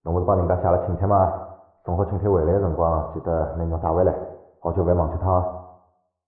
三楼/囚室/肉铺配音偷听效果处理；